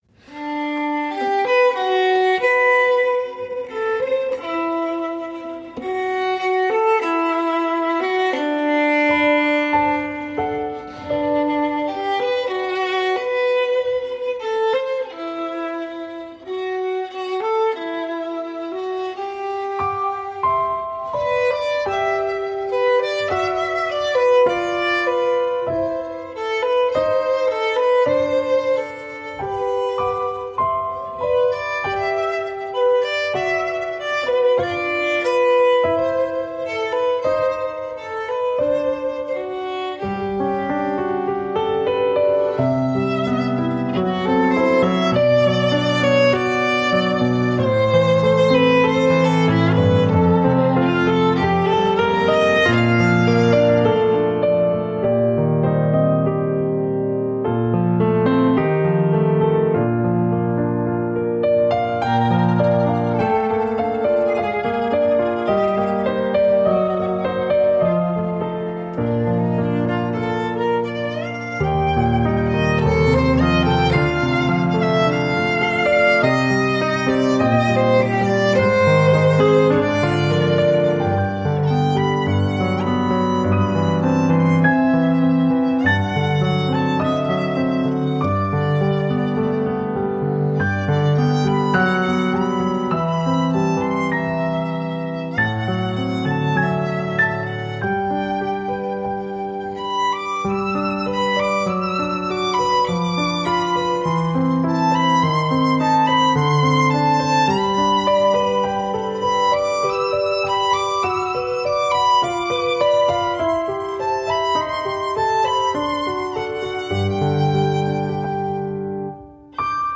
avec une forte sensibilité jazz, au sens large.